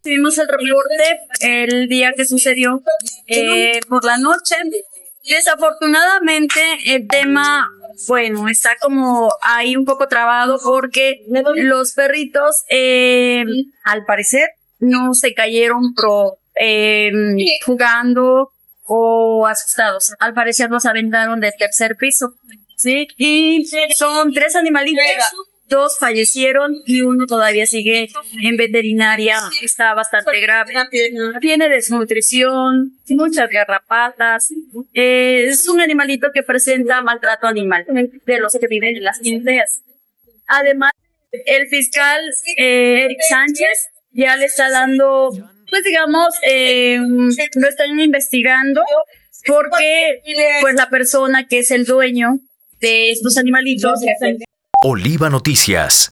En entrevista